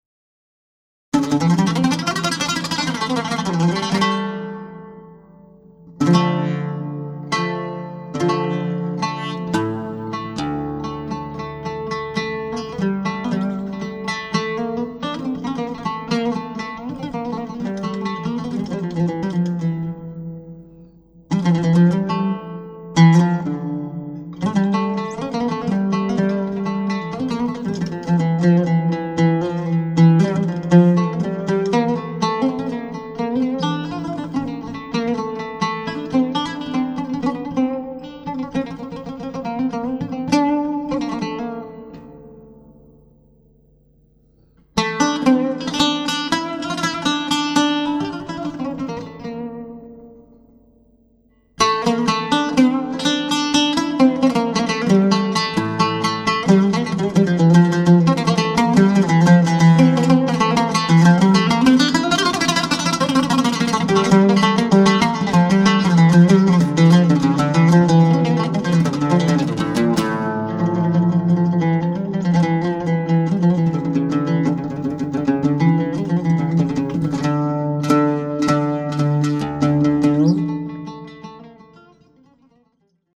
Genres: Improvisational Music on oud, Turkish Traditional.